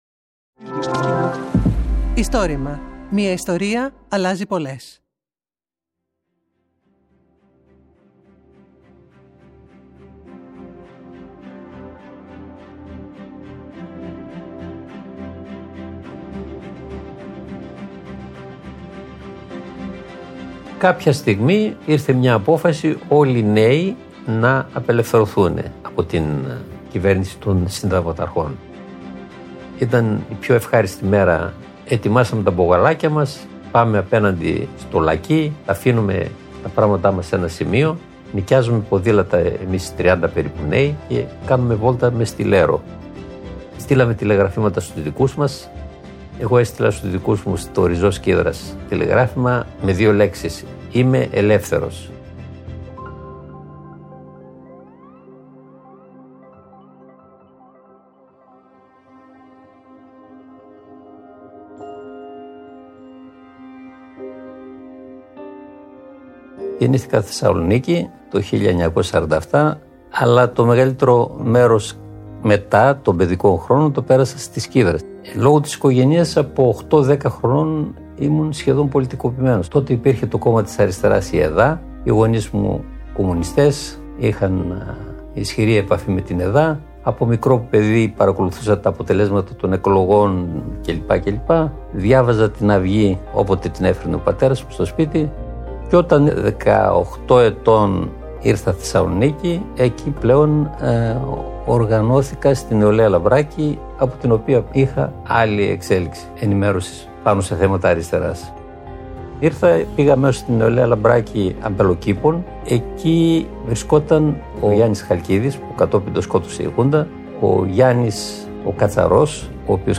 Το Istorima είναι το μεγαλύτερο έργο καταγραφής και διάσωσης προφορικών ιστοριών της Ελλάδας.